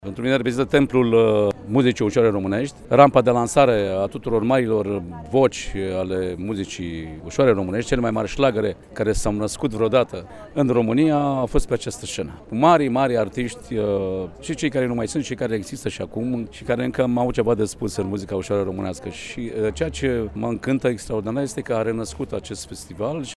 Printre cei care au susținut recitaluri s-a numărat și Marcel Pavel: